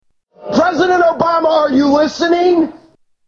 Tags: Rick Santelli Jon Stewart Jim Cramer Rants about the US economy Economic rants